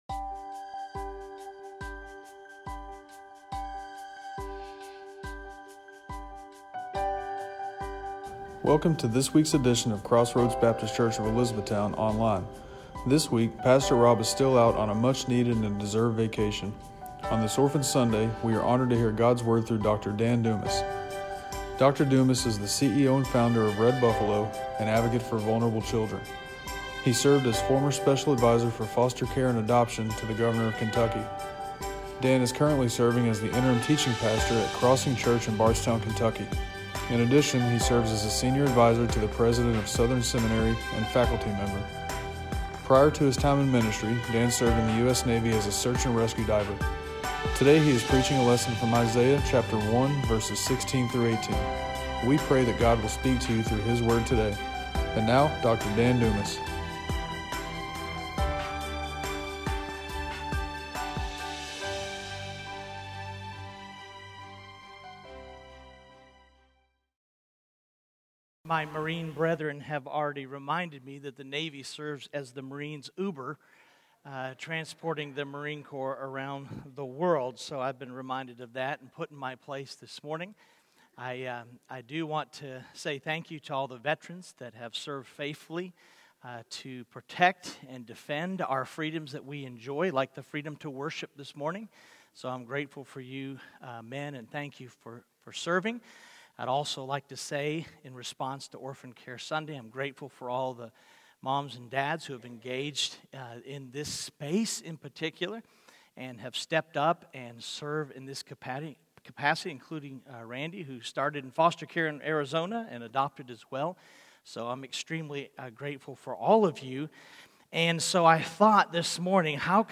Individual Sermons